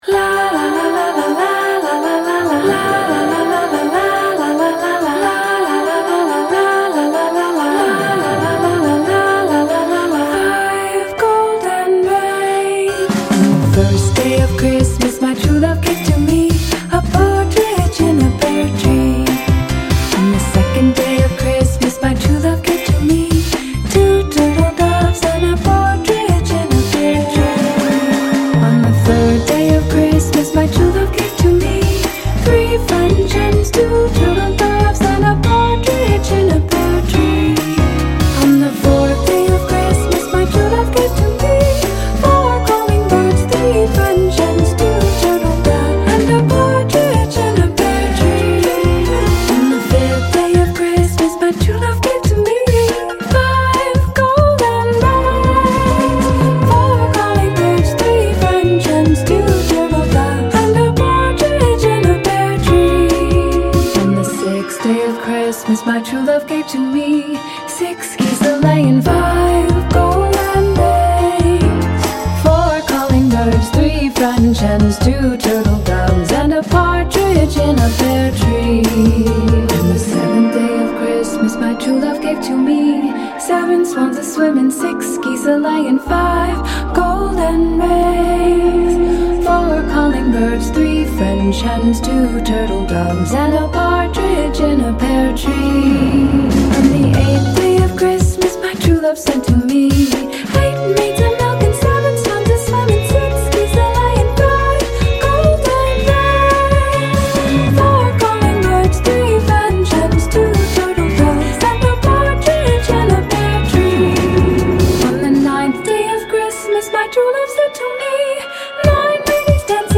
BPM99-141
Audio QualityPerfect (High Quality)
Christmas song for StepMania, ITGmania, Project Outfox
Full Length Song (not arcade length cut)